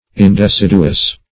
Indeciduous \In`de*cid"u*ous\